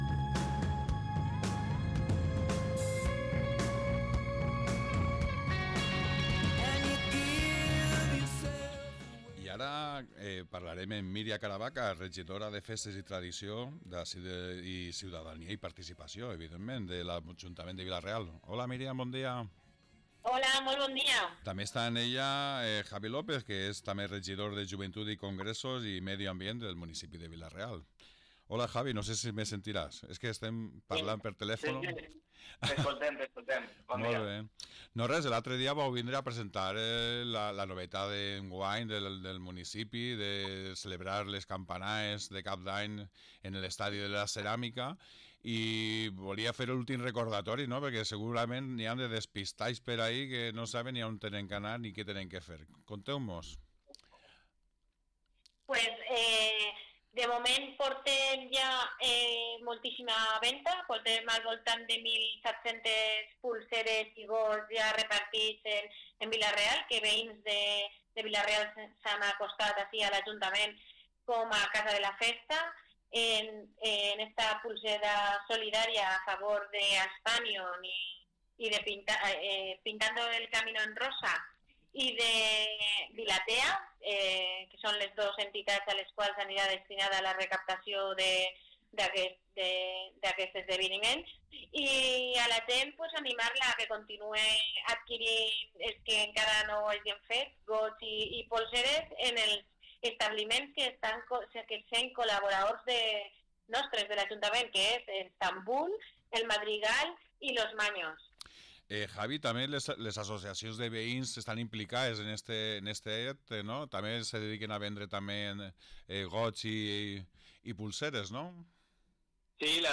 Campanades 2026, parlem amb els regidors Miriam Caravaca i Javier López